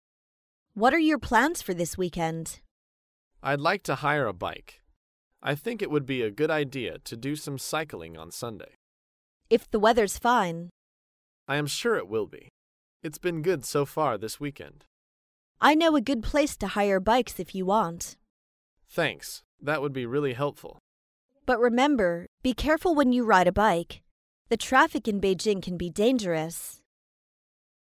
在线英语听力室高频英语口语对话 第393期:租自行车(1)的听力文件下载,《高频英语口语对话》栏目包含了日常生活中经常使用的英语情景对话，是学习英语口语，能够帮助英语爱好者在听英语对话的过程中，积累英语口语习语知识，提高英语听说水平，并通过栏目中的中英文字幕和音频MP3文件，提高英语语感。